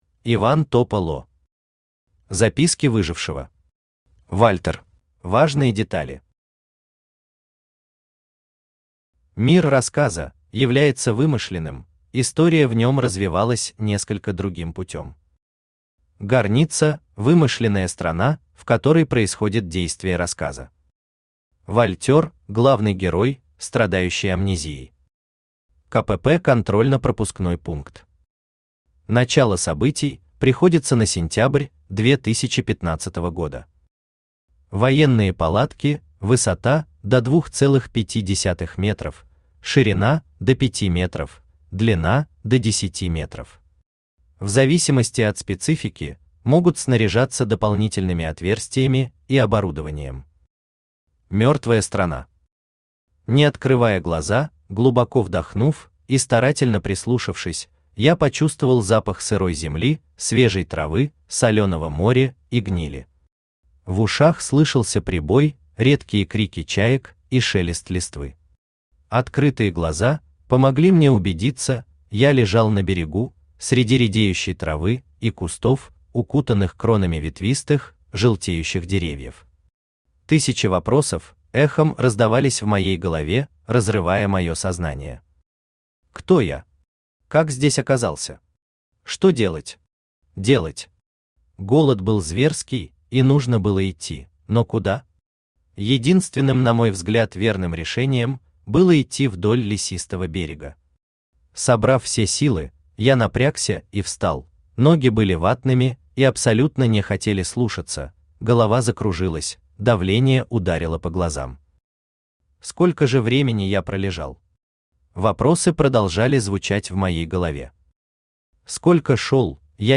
Аудиокнига Записки выжившего. Вальтер | Библиотека аудиокниг
Вальтер Автор Иван Вячеславович Топа́ло Читает аудиокнигу Авточтец ЛитРес.